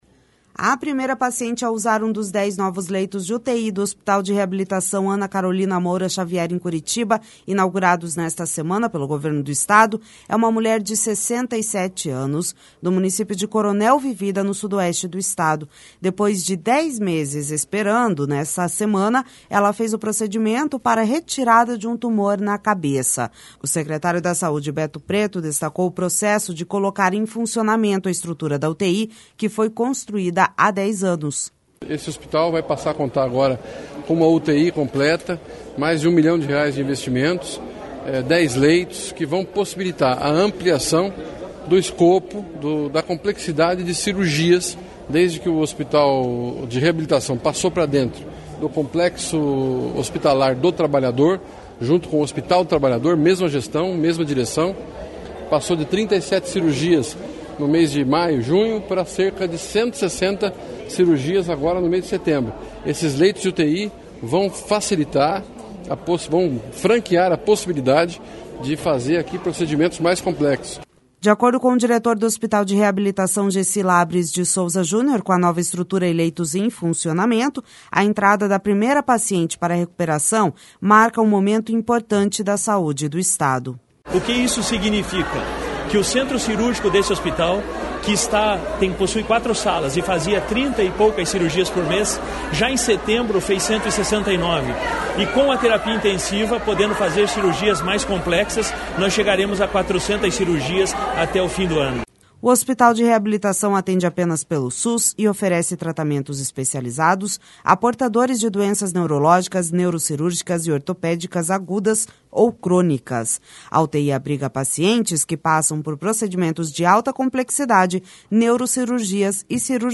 Depois de 10 meses esperando, nesta semana ela fez o procedimento para a retirada de um tumor na cabeça. O secretário da Saúde, Beto Preto, destacou o processo de colocar em funcionamento a estrutura da UTI que foi construída há dez anos.